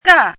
怎么读
尬 [gà]